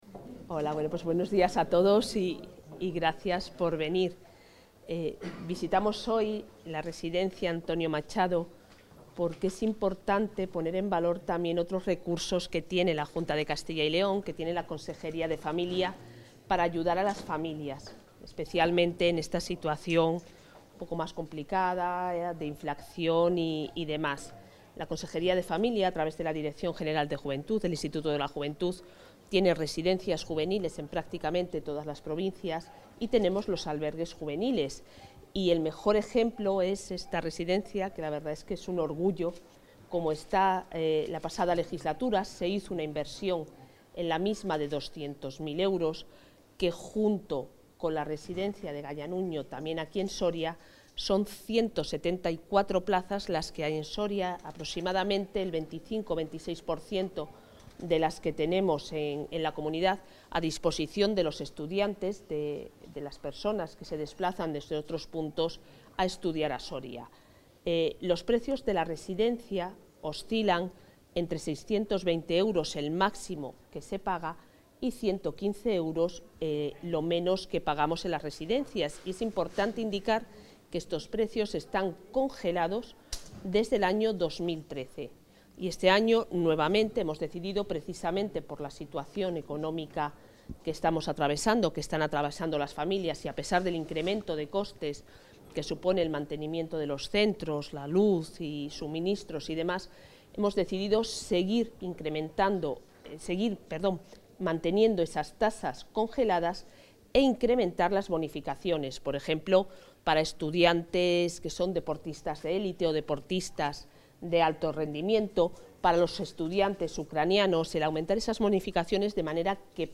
Audio consejera.